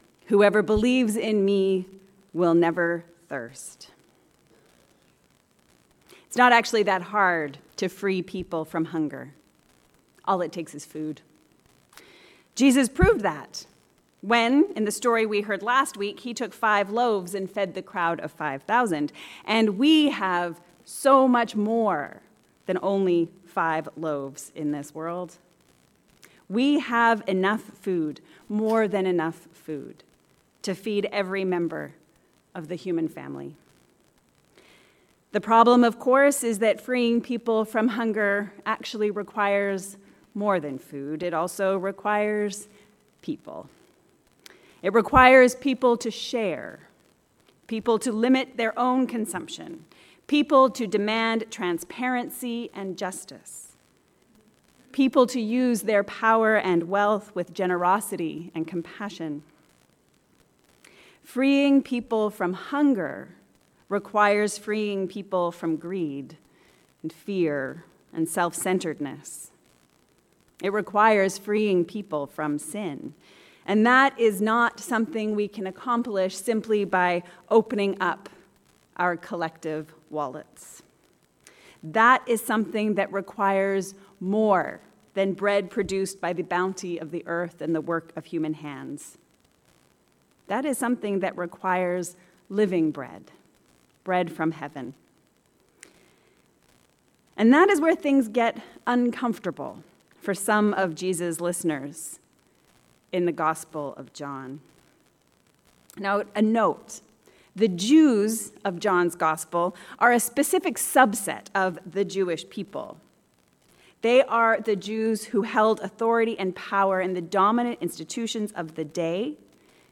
Feed the Hungry. A sermon for the 12th Sunday after Pentecost